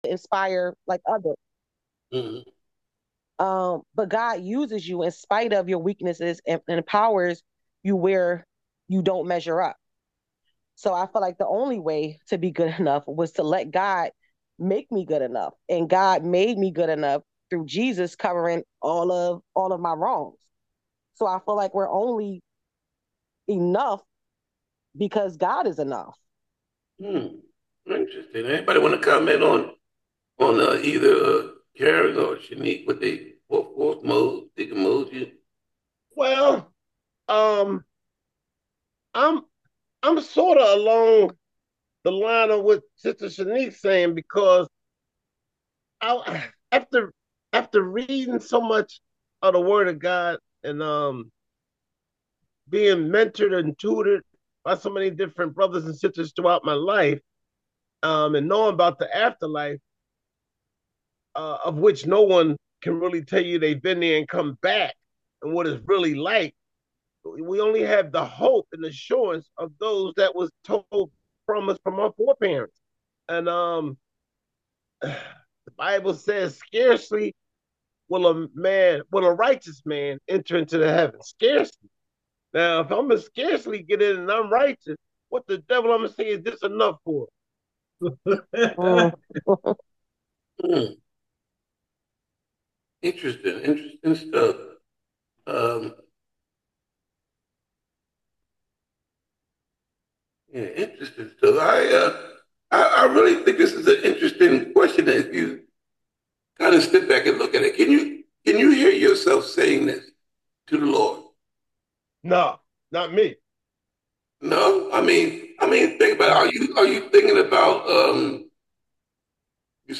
I Think I Do Enough - Bible Study - St James Missionary Baptist Church